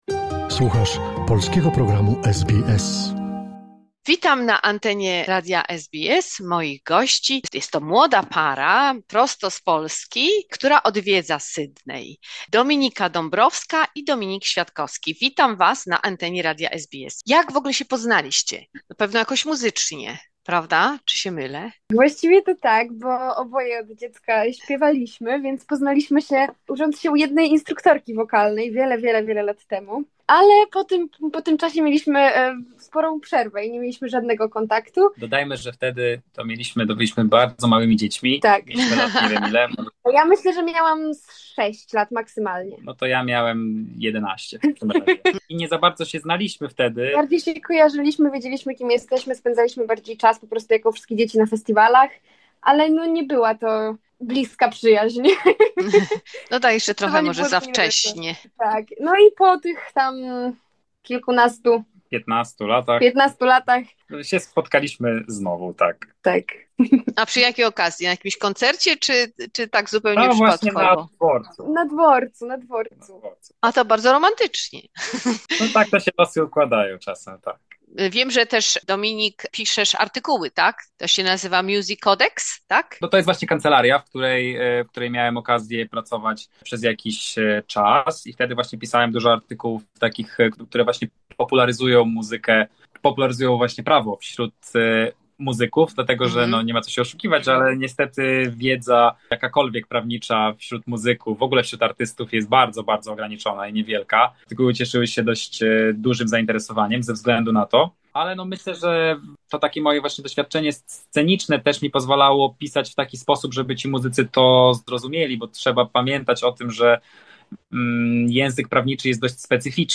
Druga część rozmowy